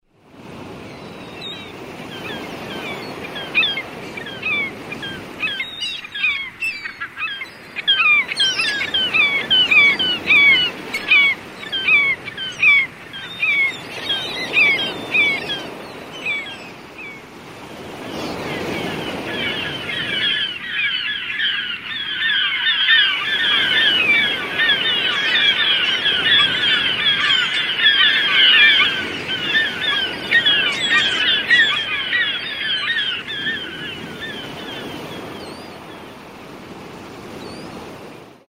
Звуки морского прибоя
На этой странице собраны звуки морского прибоя в высоком качестве: от легкого шелеста волн до мощного грохота океана.